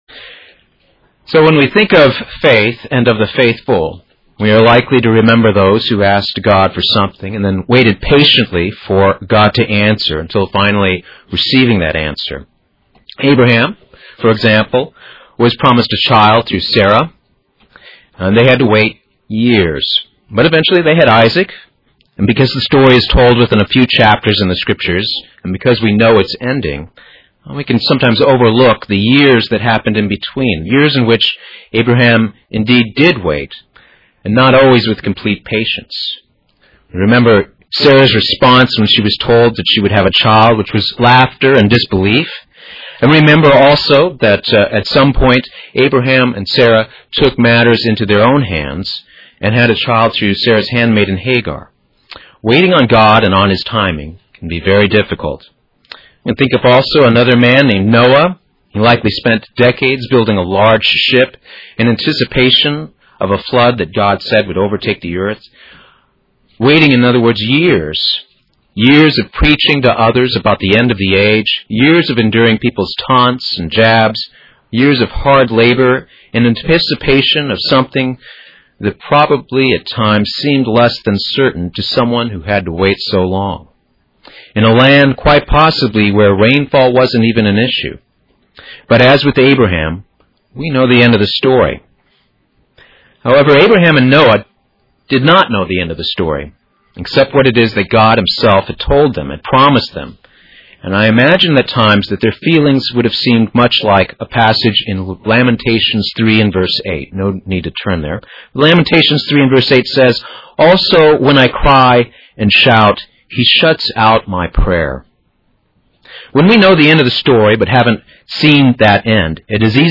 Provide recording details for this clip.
Given in Buford, GA